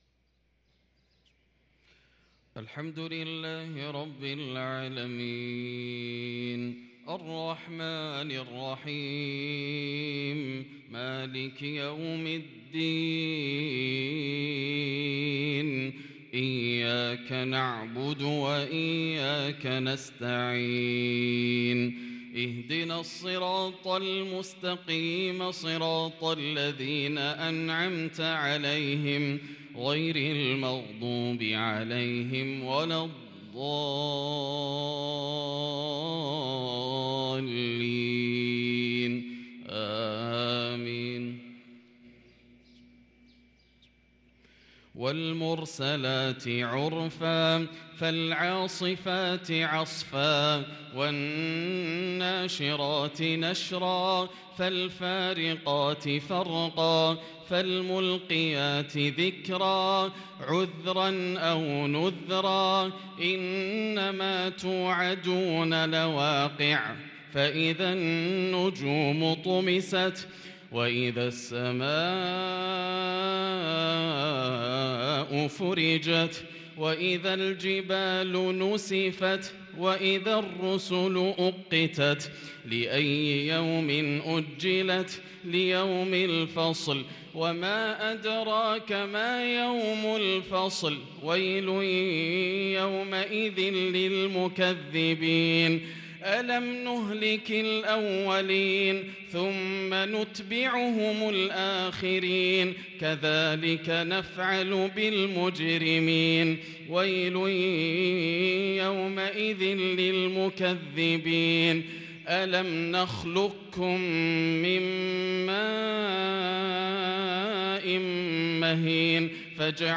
صلاة العشاء للقارئ ياسر الدوسري 6 جمادي الآخر 1444 هـ
تِلَاوَات الْحَرَمَيْن .